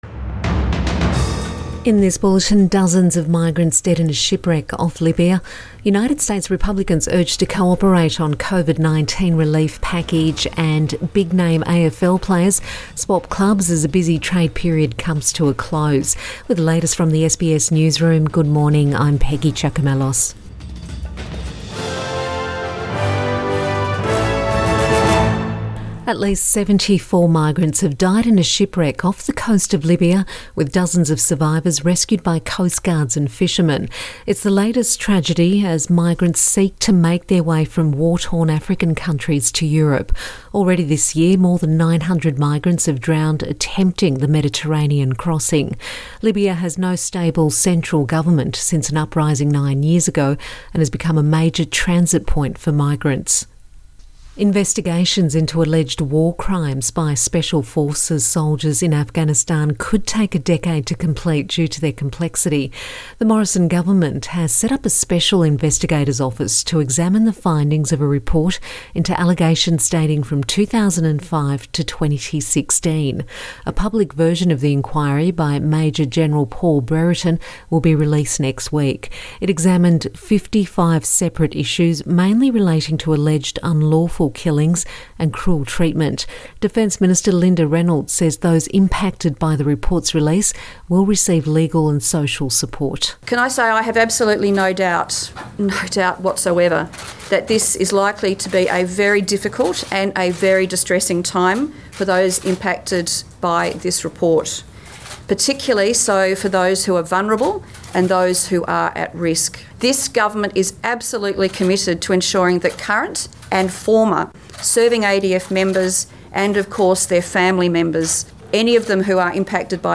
AM bulletin 13 November 2020